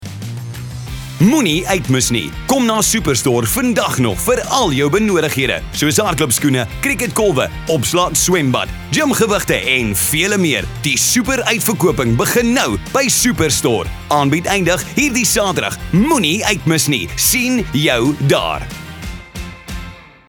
captivating, charismatic, charming, magnetic